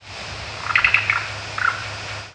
Black-billed Cuckoo Coccyzus erythropthalmus
Flight call description A low gurgling trill, typically decelerating and rising slightly in pitch.
Fig.1. Maryland May 23, 1993 (MO).
Call from a perched bird on the breeding grounds.